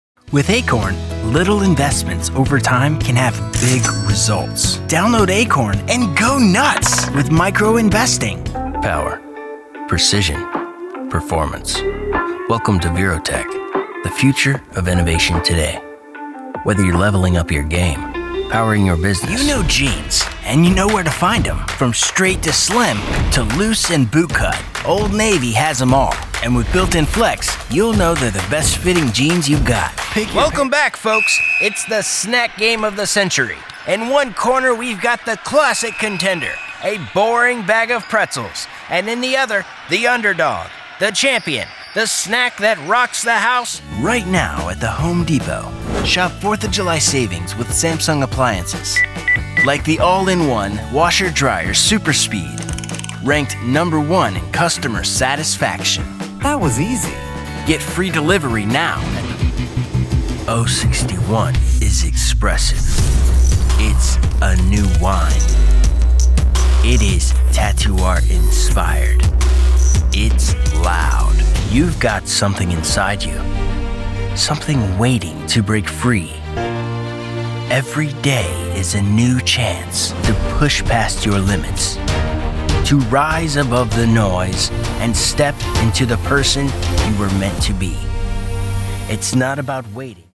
Englisch (US) voice actor